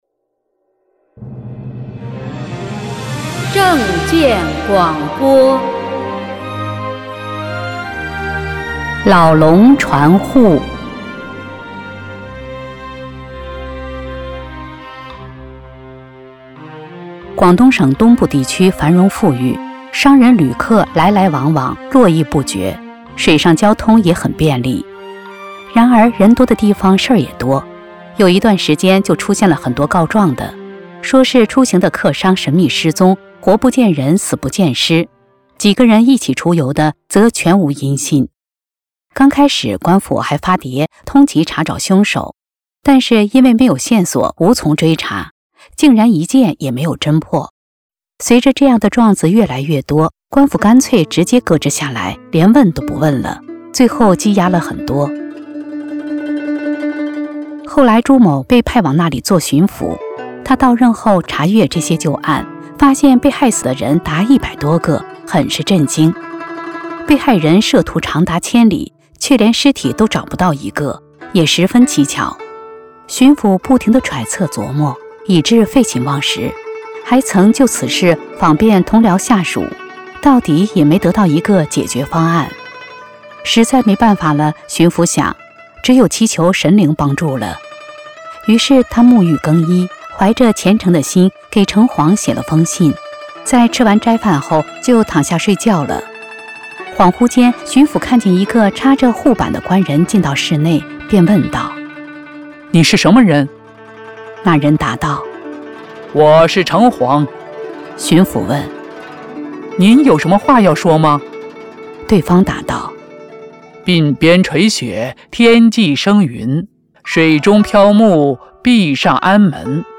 正見廣播（音頻）：老龍船戶